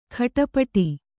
♪ khaṭapaṭi